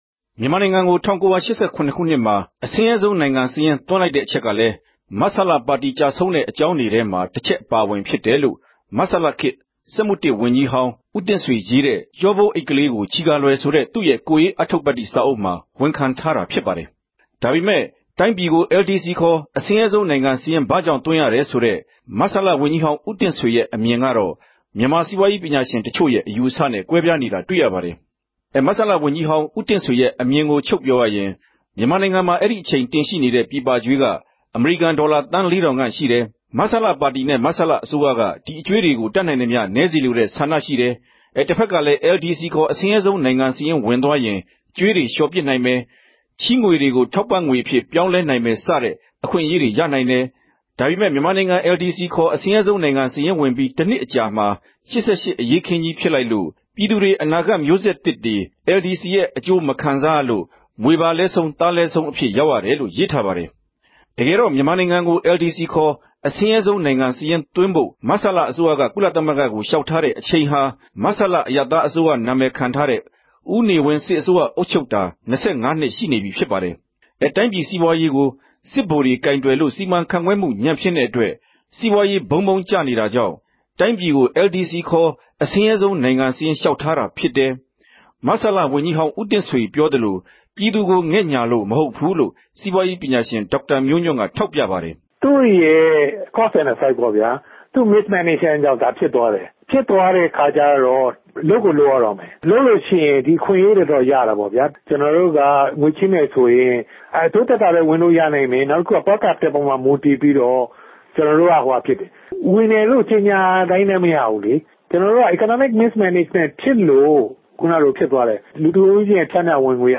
သုံးသပ်တင်ပြချက်